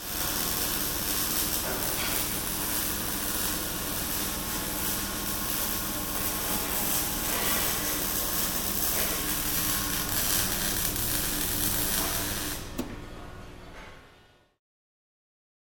Welding at NEA Engineering